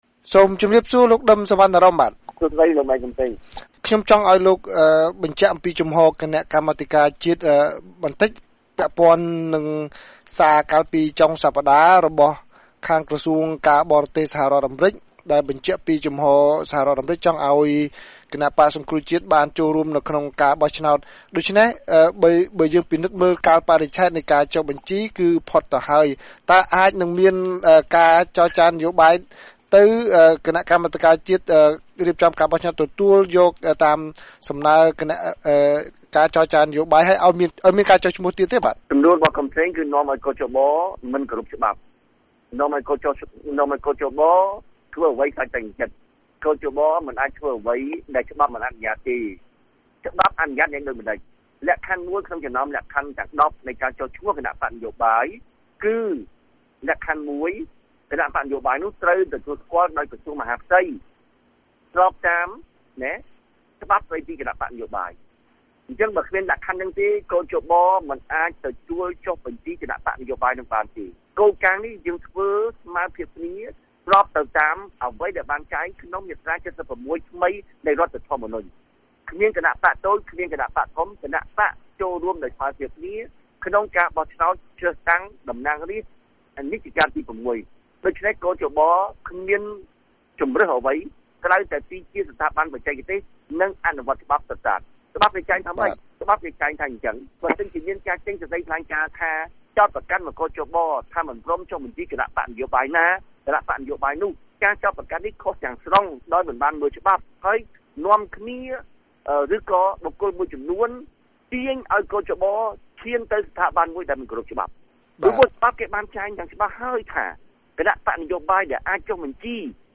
បទសម្ភាសន៍ VOA៖ គ.ជ.បថាហួសពេលចុះឈ្មោះបក្សសង្គ្រោះជាតិតាមការស្នើរបស់សហរដ្ឋអាមេរិក